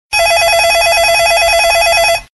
Категория : Звуковые эффекты